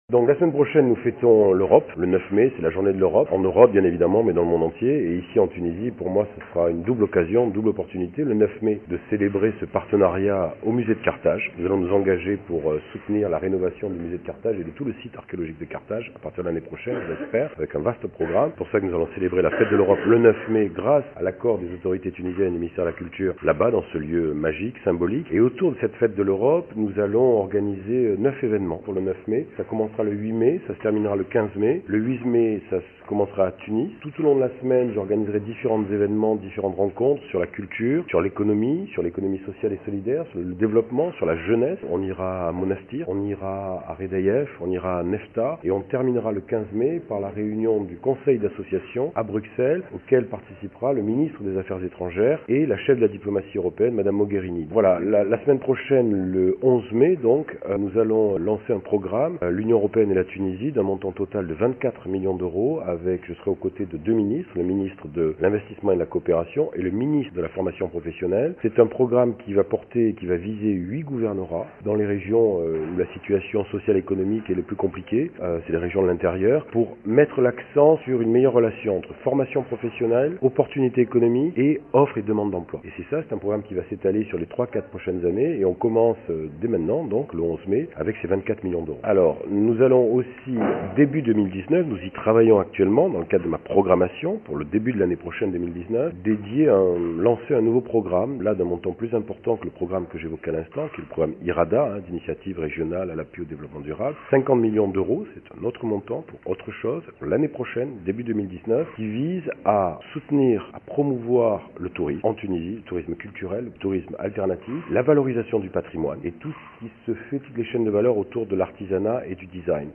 قدم رئيس المفوضية الأوروبية بتونس باتريس برغاميني، خلال ندوة صحفية عقدت اليوم الخميس، برنامج الاحتفال بالاسبوع الأوروبي الذي سيمتد من 8 الى 13 ماي .